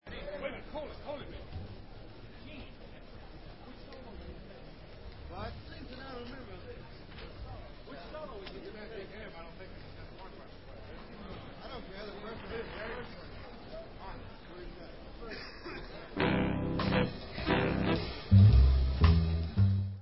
W/his orchestra (patio garden ballroom, utah 1962)